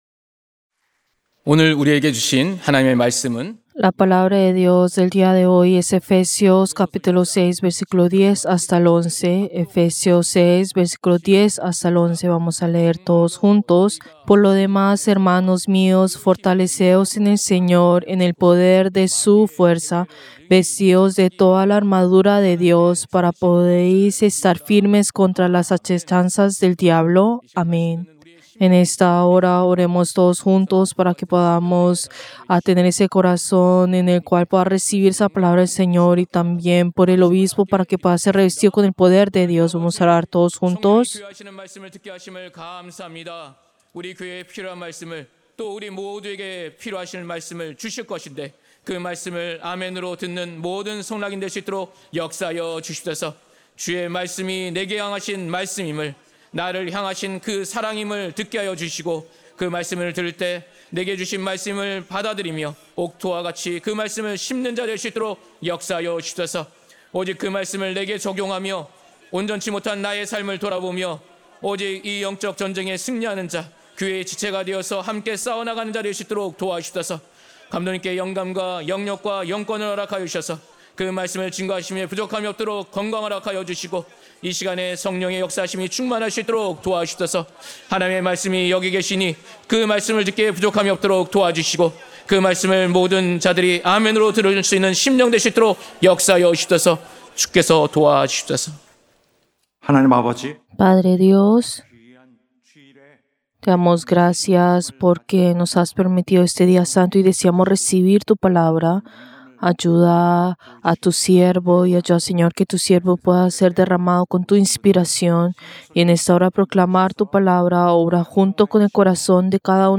Servicio del Día del Señor del 15 de junio del 2025